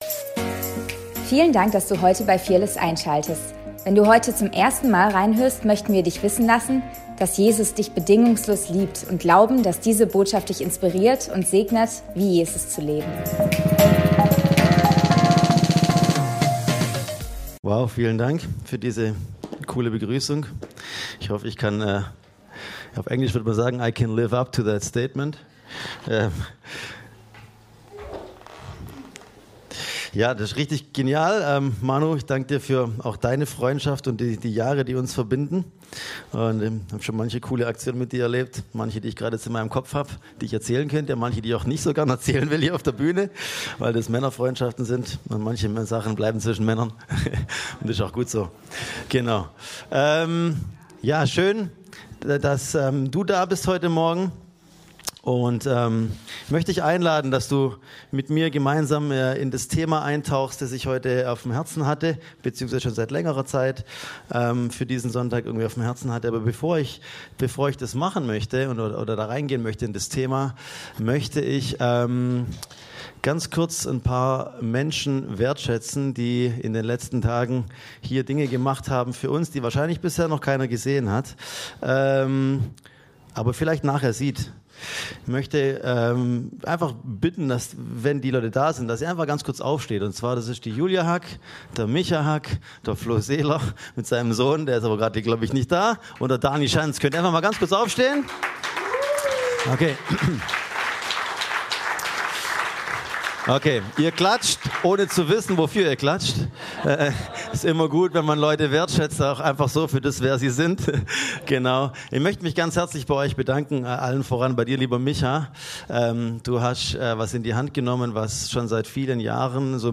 Predigt vom 27.10.2024